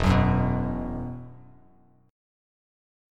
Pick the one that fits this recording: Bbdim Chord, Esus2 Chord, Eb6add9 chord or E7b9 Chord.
Esus2 Chord